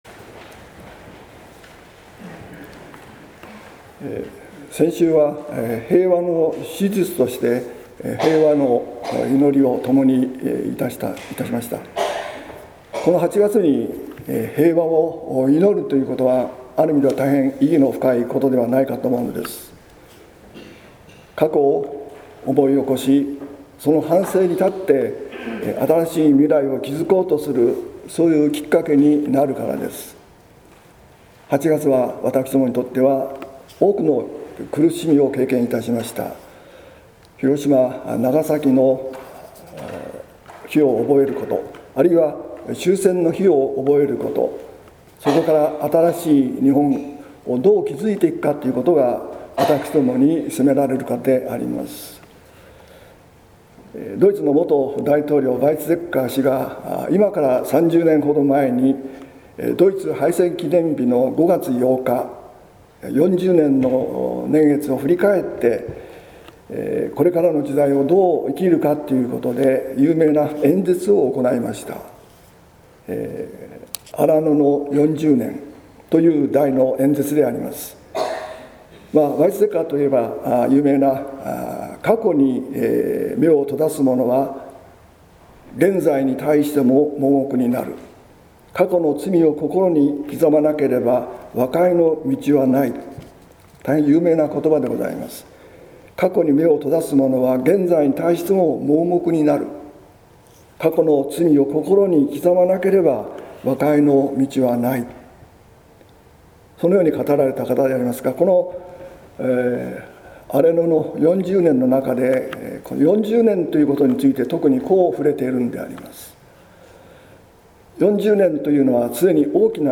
説教「福音を拒むとき」（音声版）